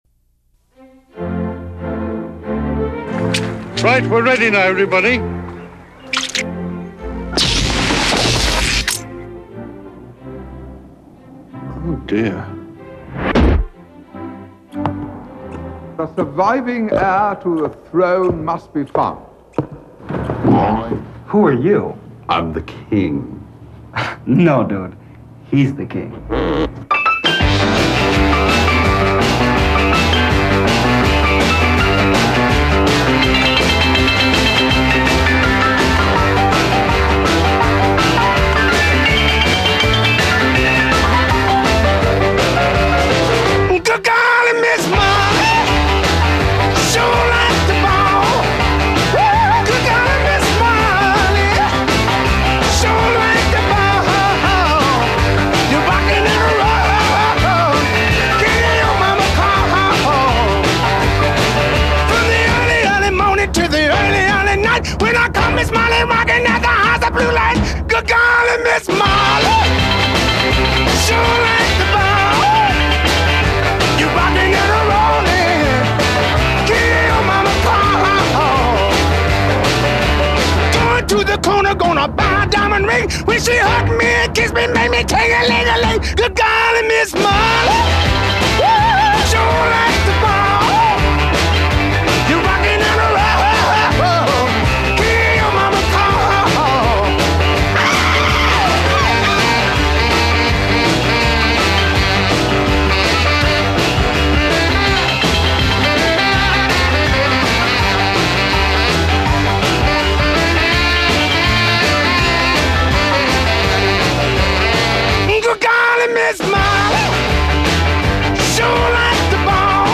но звук желает лучшего.